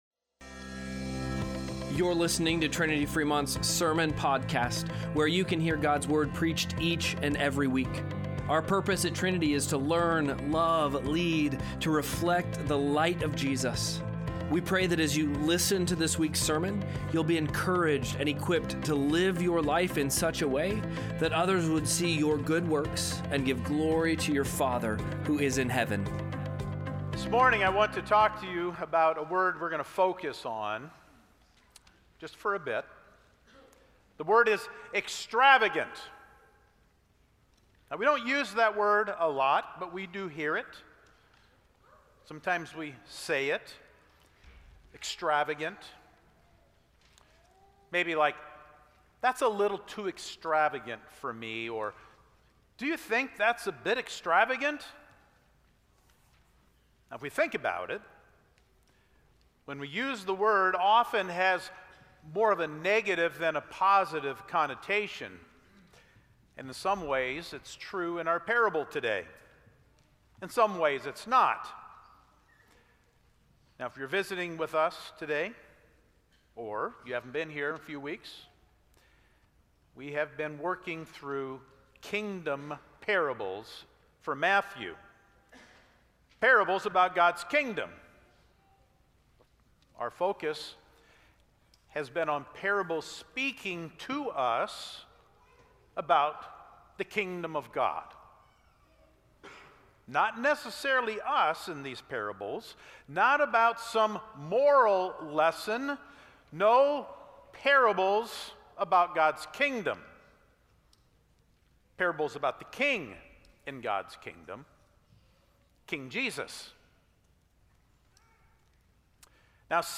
1-25-Sermon-Podcast-.mp3